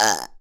pgs/Assets/Audio/Comedy_Cartoon/comedy_burp_02.wav
comedy_burp_02.wav